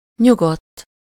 Ääntäminen
IPA: /tʁɑ̃.kil/